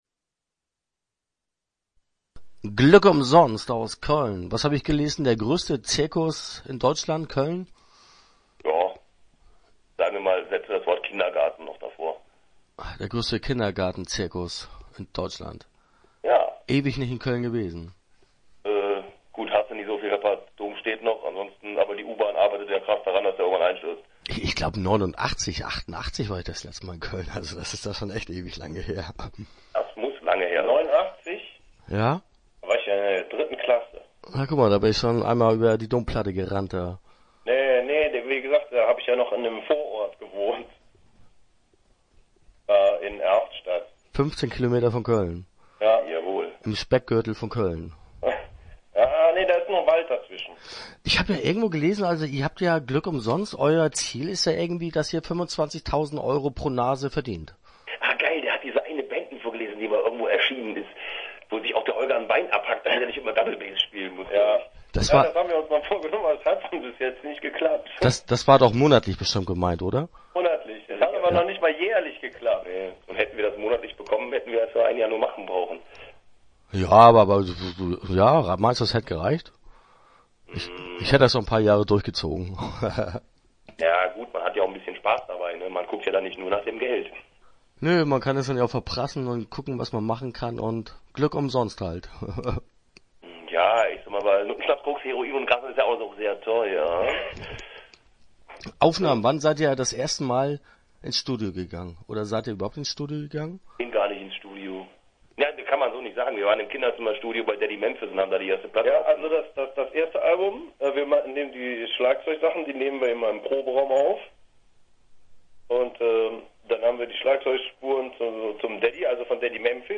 Start » Interviews » Glück Umsonst